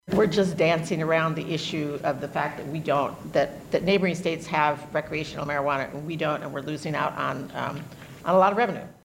SENATOR JANET WEINER OF IOWA CITY SAYS IOWA’S MEDICAL MARIJUANA PROGRAM IS REALLY LITTLE — FOR A REASON.